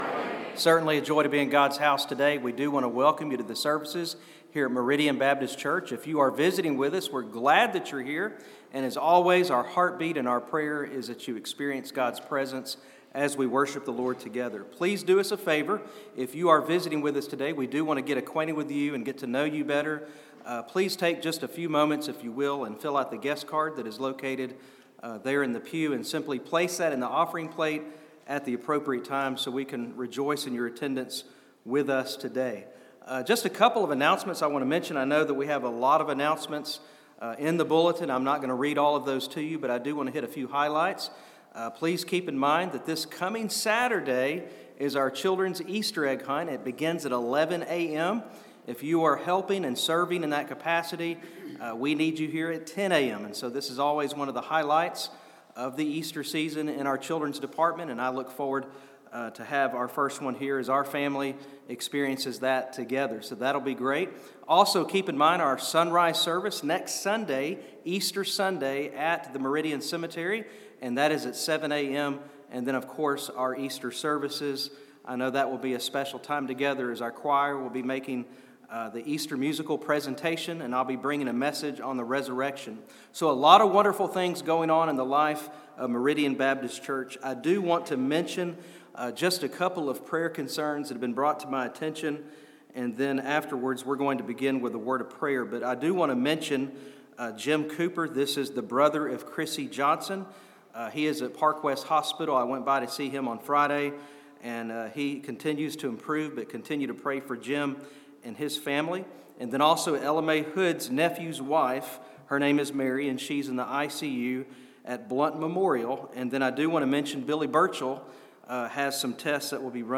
SUNDAY SERVICES ON AUDIO